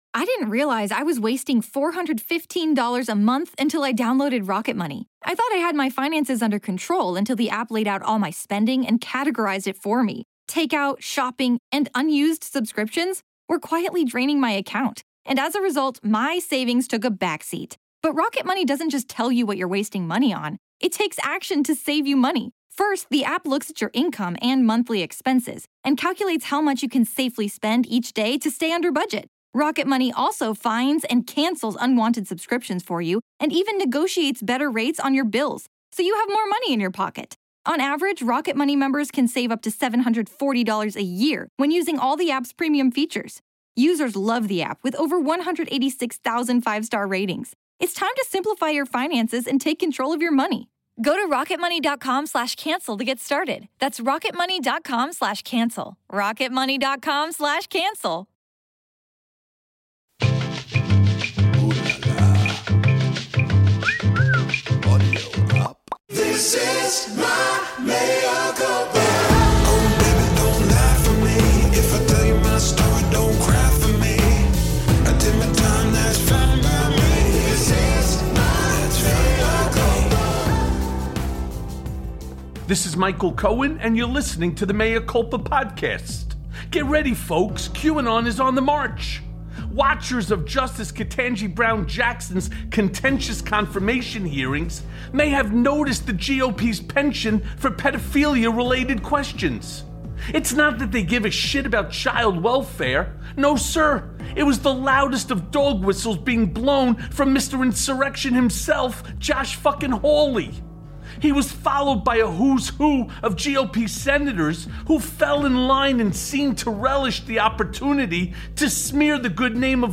We talk with former New York Times investigative reporter and bestselling author Kurt Eichenwald about what it all means.